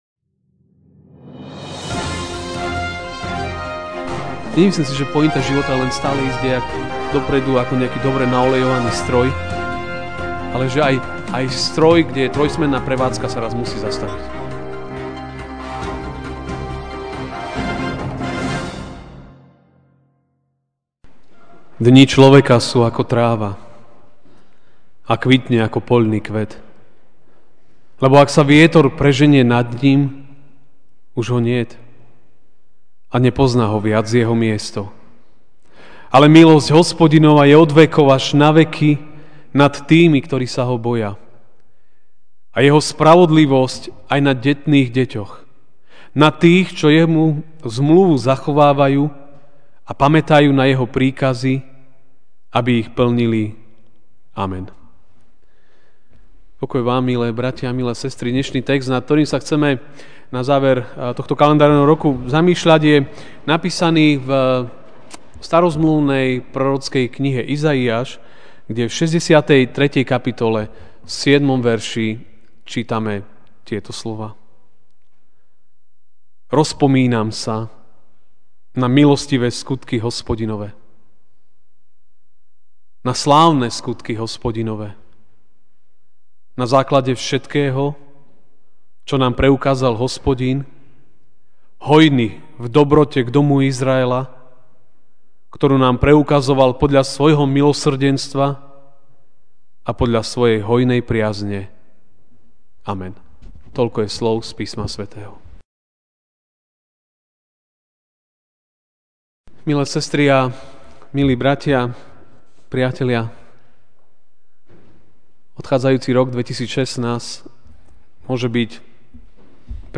dec 31, 2016 Rozpomínam sa MP3 SUBSCRIBE on iTunes(Podcast) Notes Sermons in this Series Kázeň: (Iz. 63,7) Rozpomínam sa na milostivé skutky Hospodinove, na slávne skutky Hospodinove na základe všetkého, čo nám preukázal Hospodin, hojný v dobrote k domu Izraela, ktorú nám preukazoval podľa svojho milosrdenstva a podľa svojej hojnej priazne.